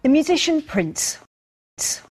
adding t so that the name sounds the same as prints